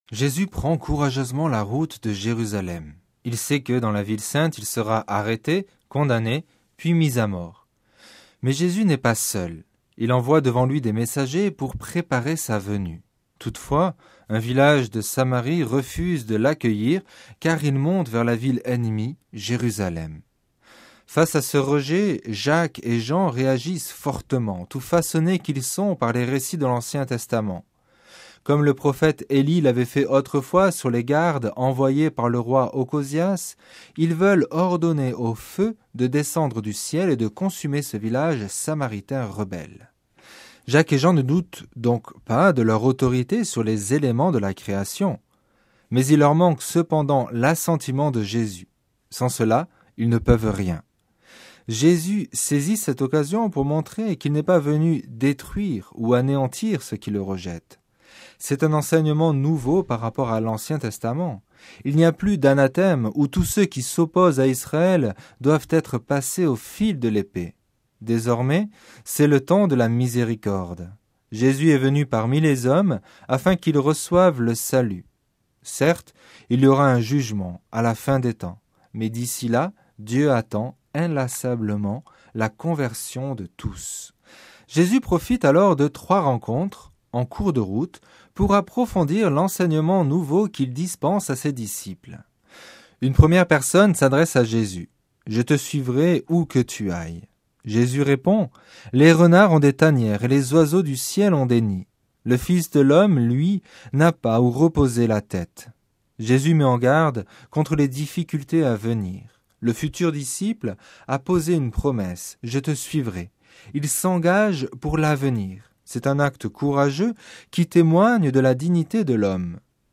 Le commentaire de l'Evangile du dimanche 30 juin